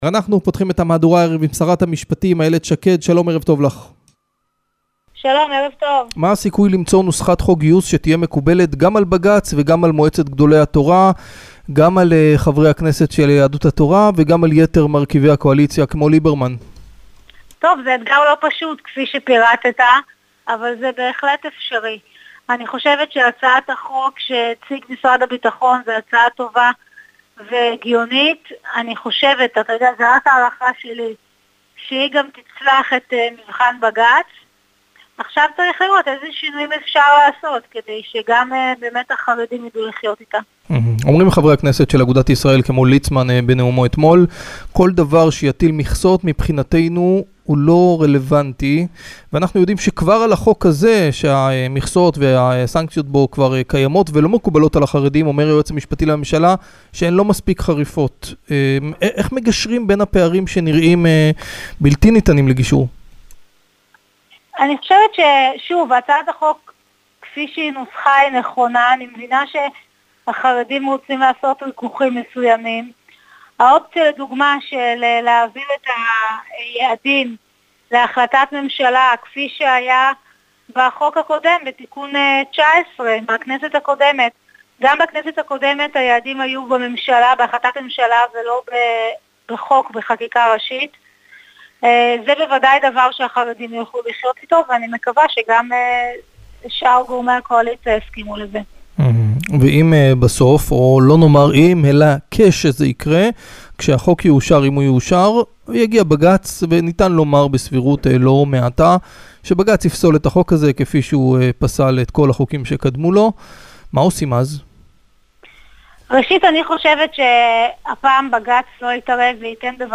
Полностью интервью с Айелет Шакед можно прослушать, перейдя по этой ссылке.